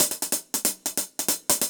Index of /musicradar/ultimate-hihat-samples/140bpm
UHH_AcoustiHatB_140-02.wav